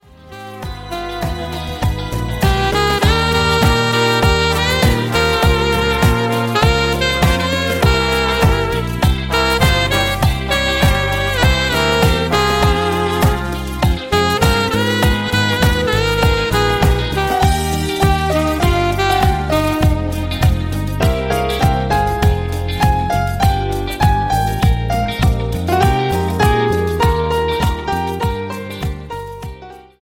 CUMBIA  (4.08)